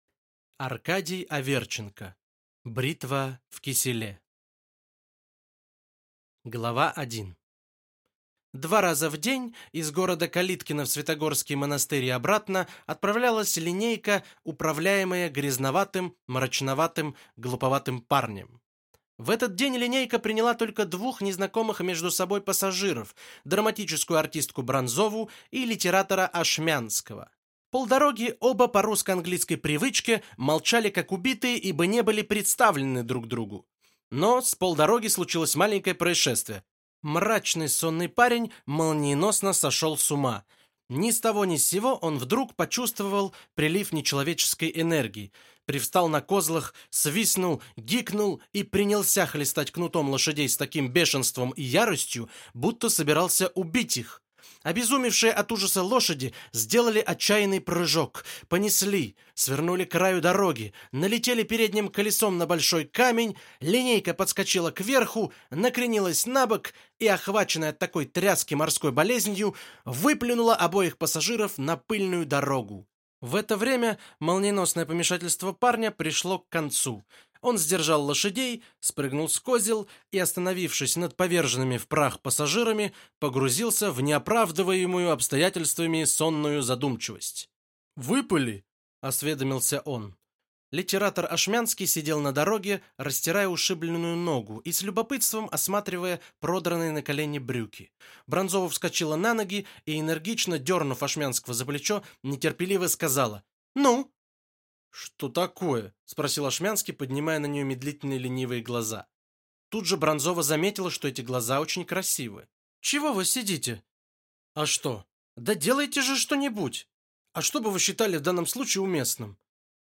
Аудиокнига Бритва в киселе | Библиотека аудиокниг